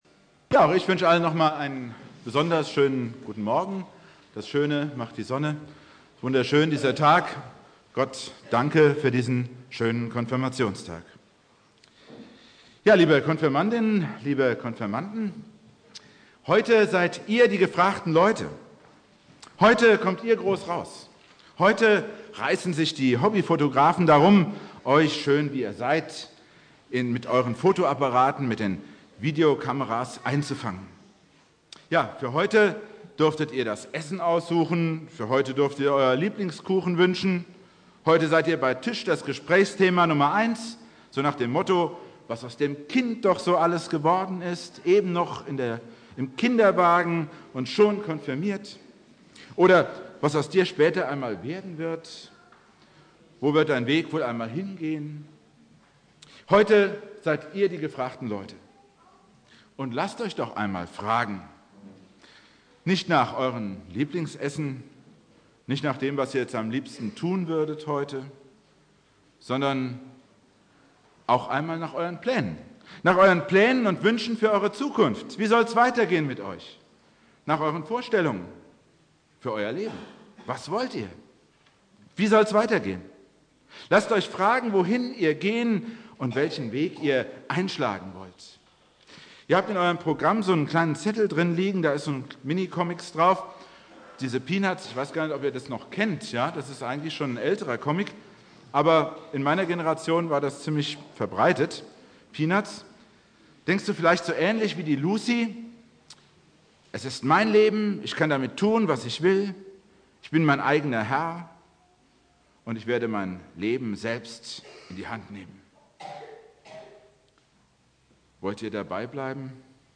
Predigt
Thema: (Konfirmation) Bibeltext: Johannes 15,5 Dauer